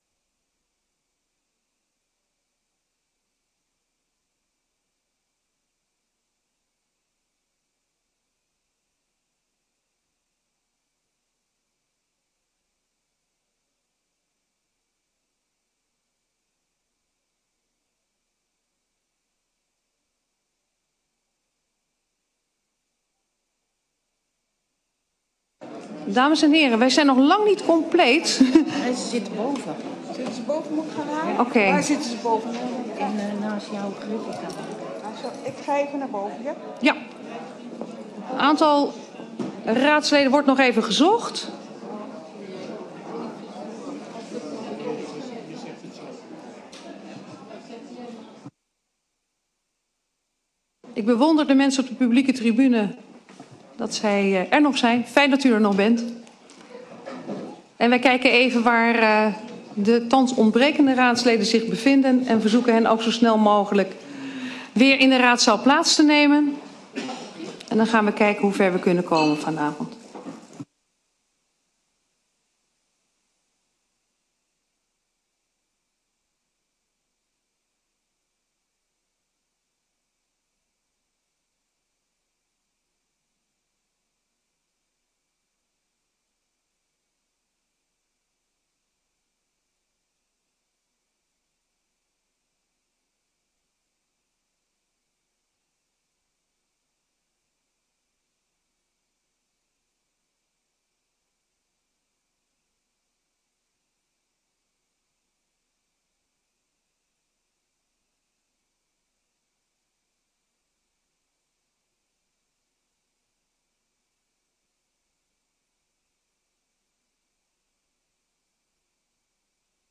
Gemeenteraad 17 april 2025 20:00:00, Gemeente Woerden